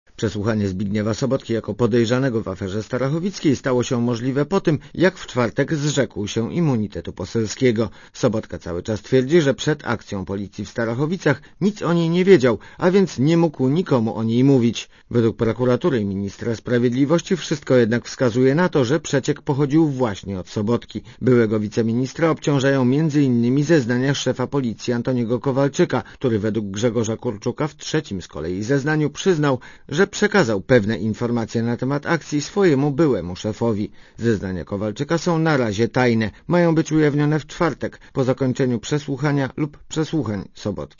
Komentarz audio (160Kb)